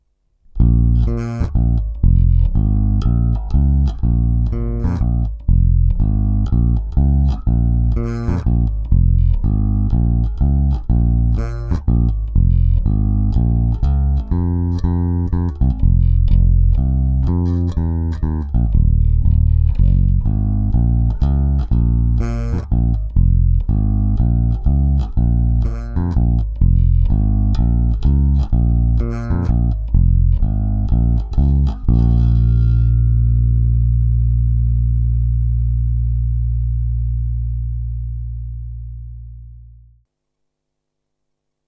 1) mikrofonem